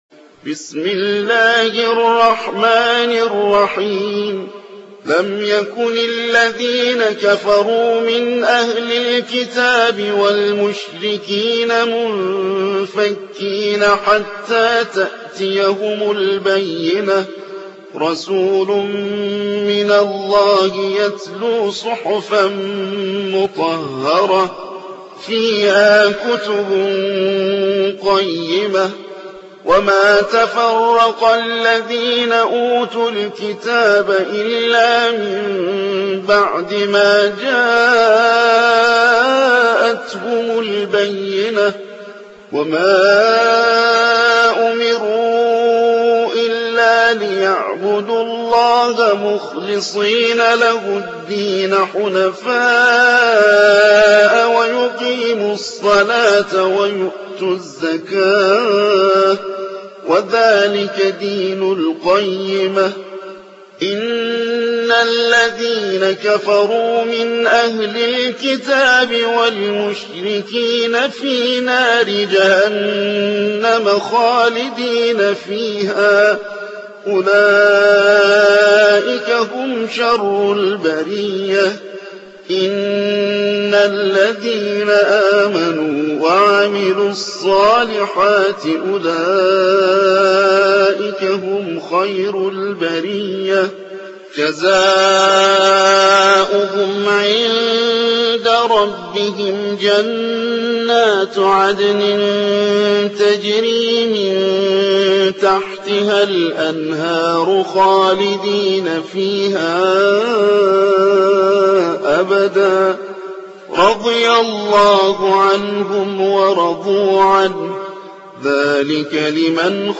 98. سورة البينة / القارئ